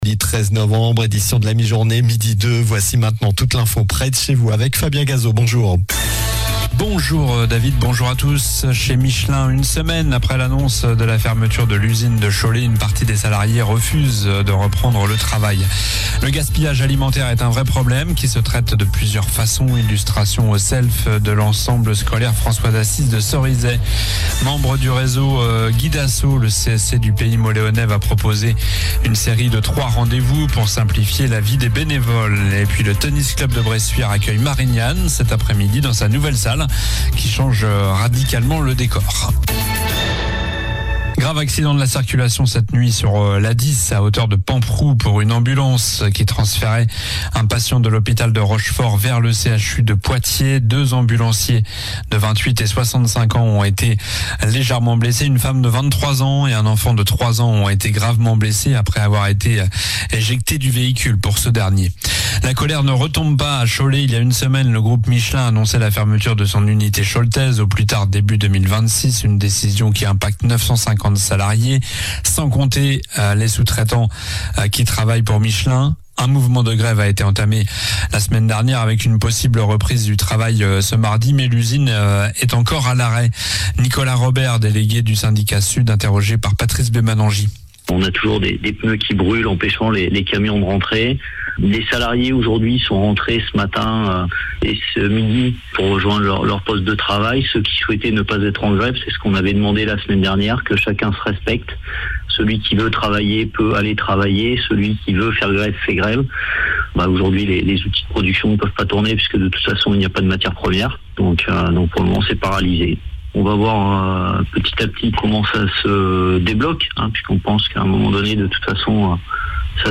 Journal du mercredi 13 novembre (midi)